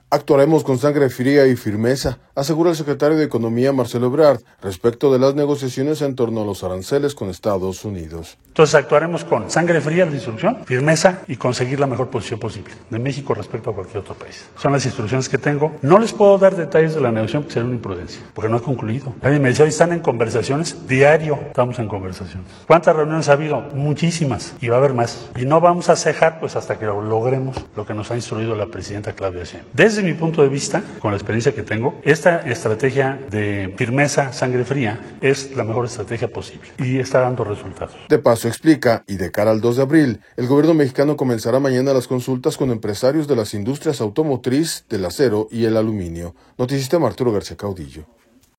Actuaremos con sangre fría y firmeza, asegura el secretario de Economía, Marcelo Ebrard, respecto de las negociaciones en torno a los aranceles con Estados Unidos.